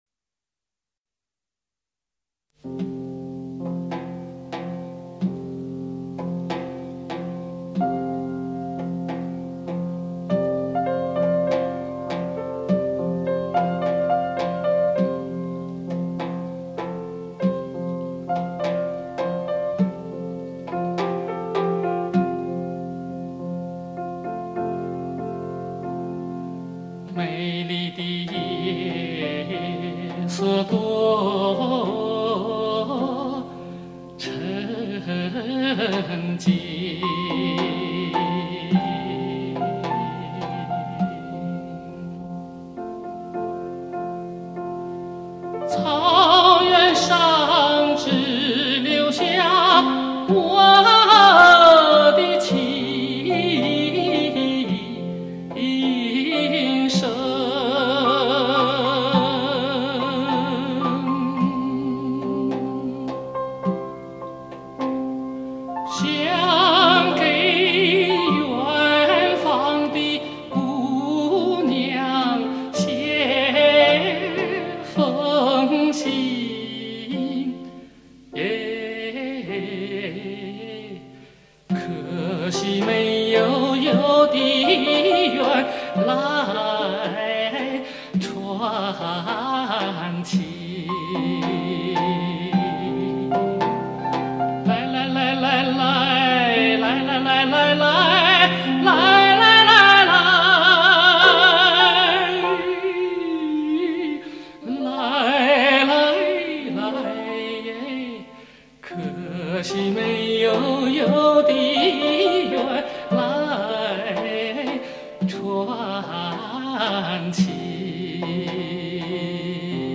轰动数载，巅峰演绎，永远最真最美的歌声